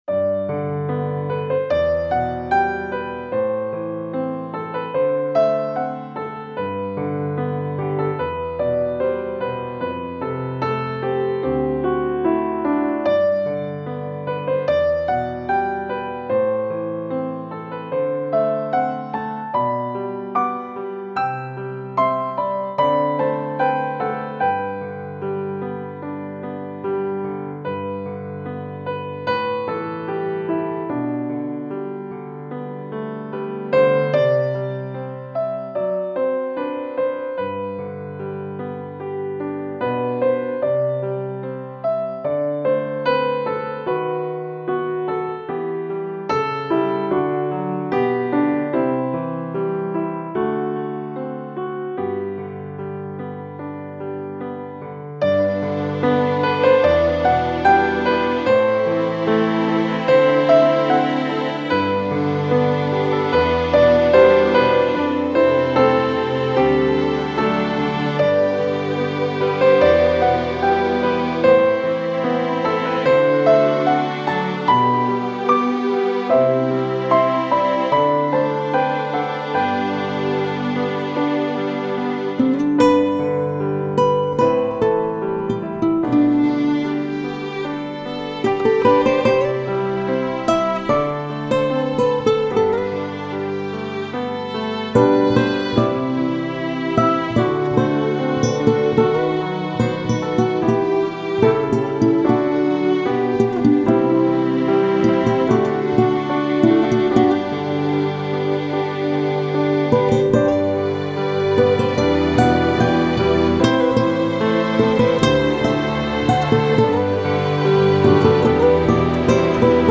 最简单的音符